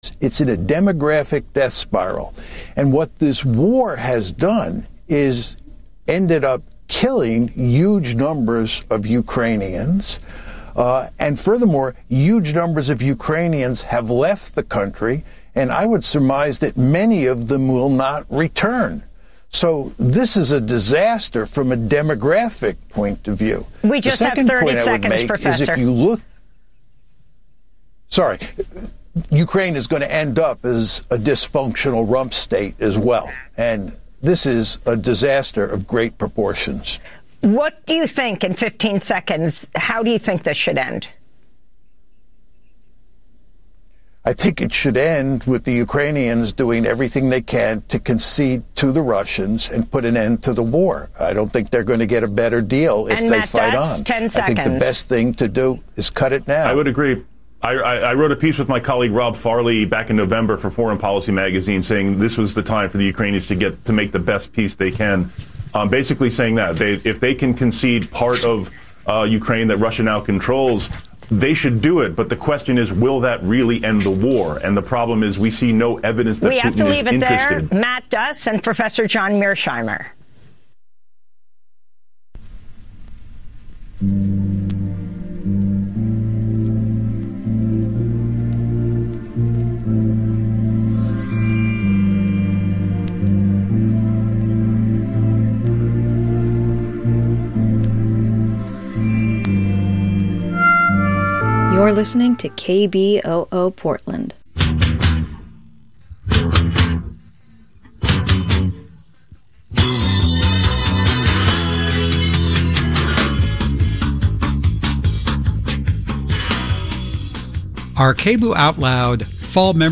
Evening News on 08/15/25